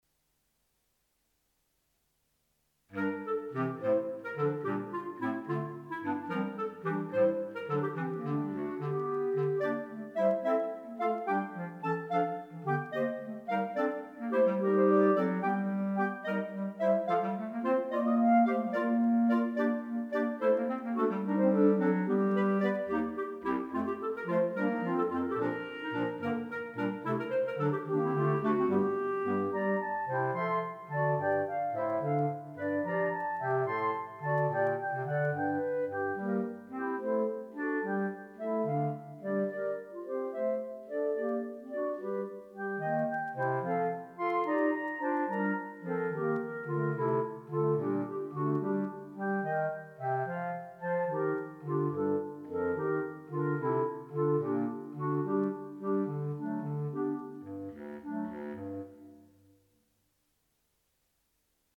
Instrumentation:3 Clarinet, Bass Cl.